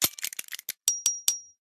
repair3.ogg